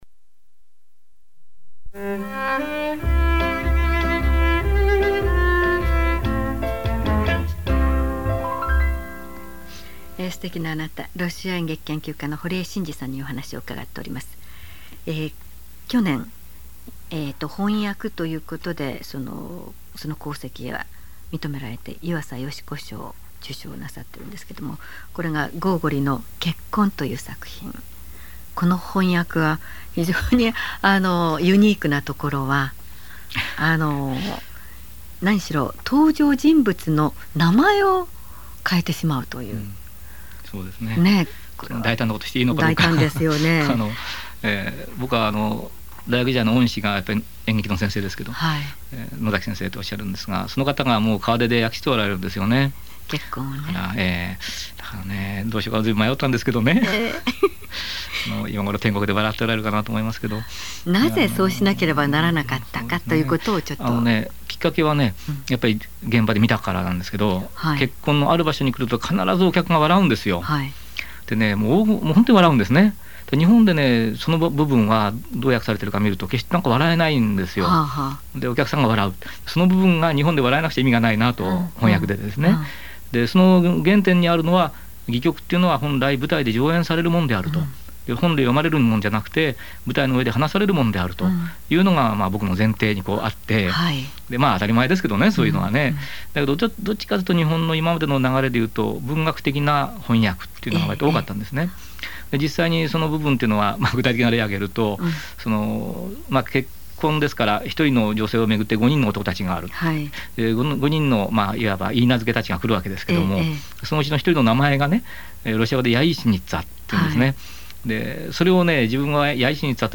ＮＨＫラヂオ「土曜ほっとタイム」に出演したときの音声
土曜ほっとタイムの「素敵なあなた」というコーナーです（ウフフ）。山根基世アナウンサーが聞き役です。